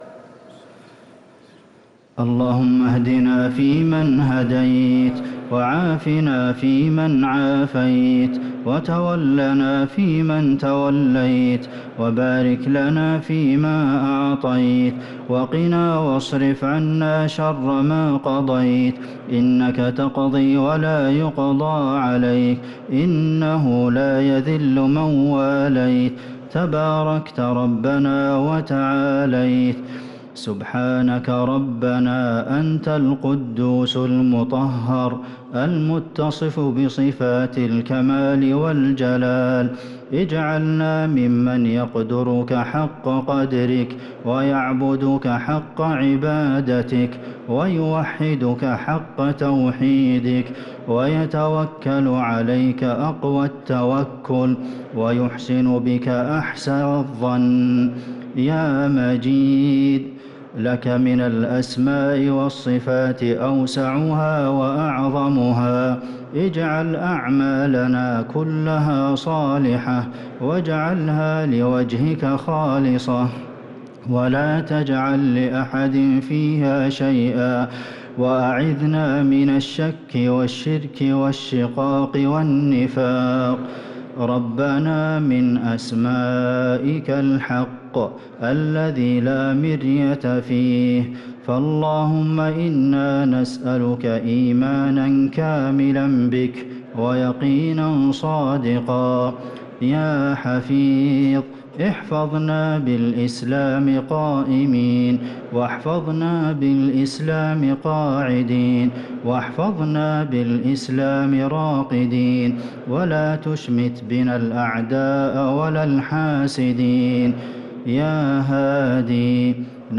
دعاء القنوت ليلة 18 رمضان 1444هـ | Dua 18 st night Ramadan 1444H > تراويح الحرم النبوي عام 1444 🕌 > التراويح - تلاوات الحرمين